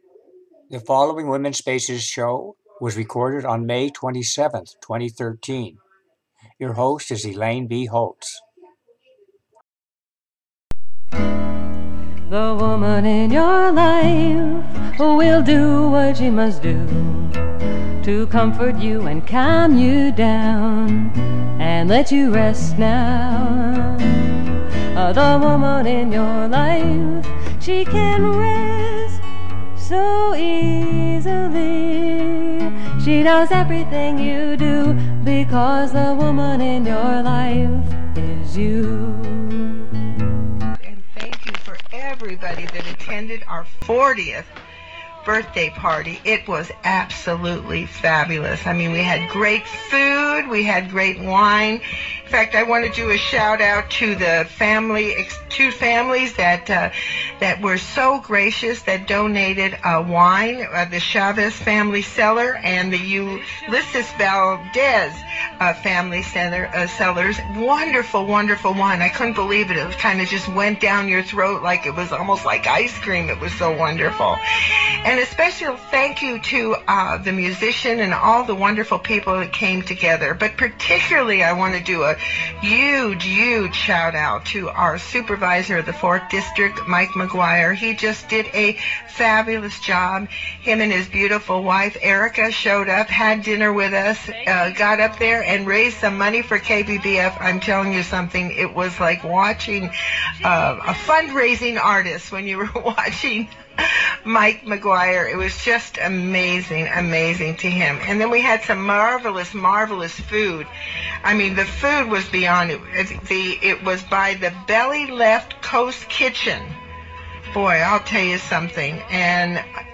interview
Broadcast from the Santa Rosa, California studio of Radio KBBF 89.1 FM